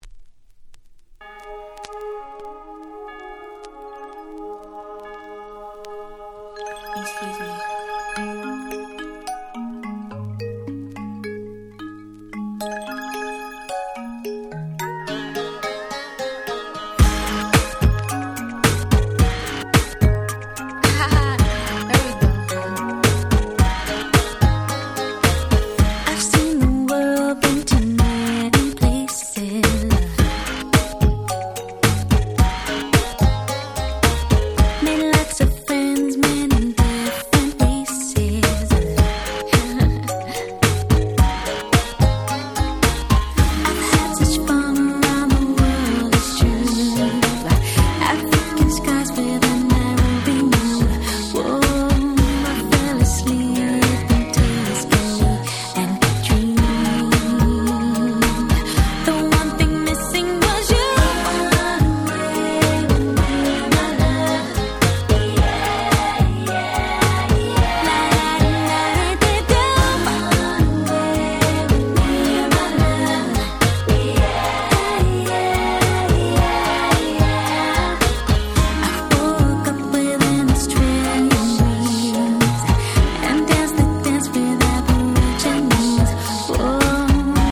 95' Super Hit R&B / Vocal House !!